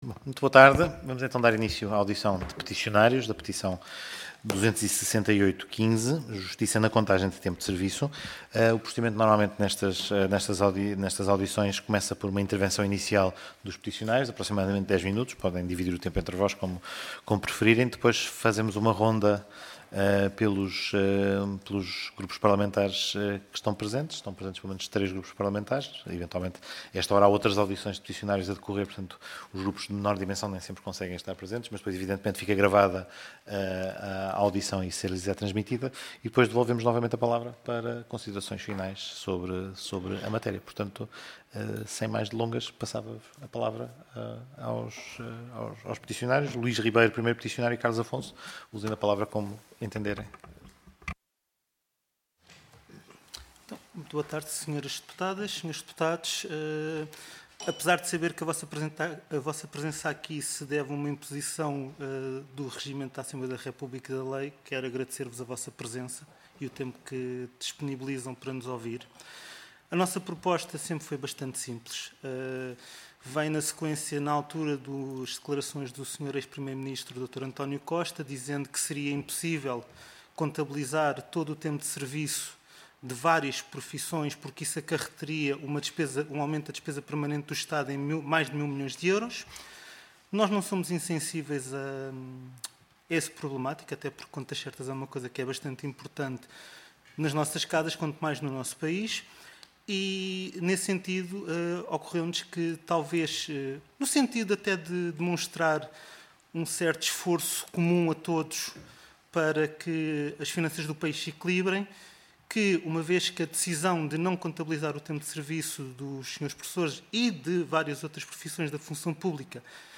Comissão de Transparência e Estatuto dos Deputados Audição Parlamentar